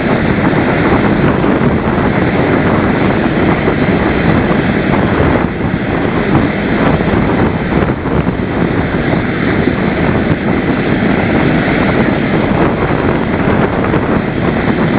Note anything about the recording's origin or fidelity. Then we went to Namafjall Hverir, an active hot springs area, before going to our lodging in the Myvatn town of Reykjahlid.